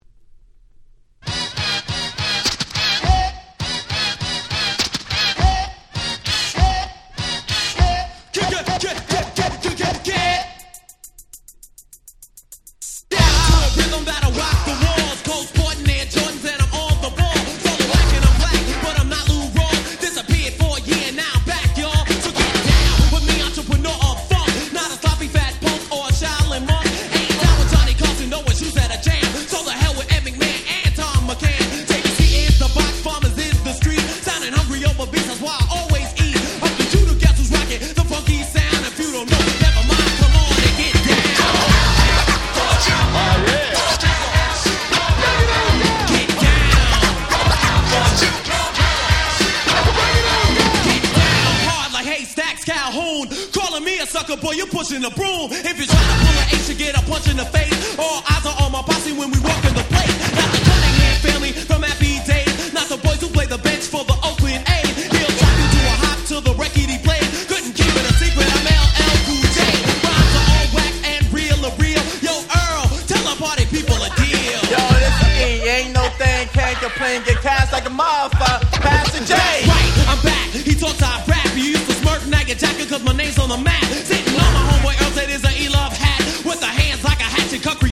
87' Super Hit Hip Hop !!
80's Old School オールドスクール Middle ミドルスクール Boom Bap ブーンバップ